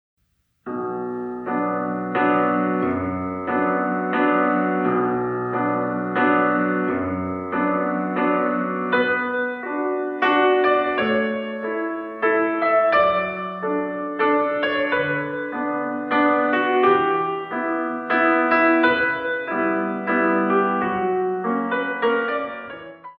Pianist
In 3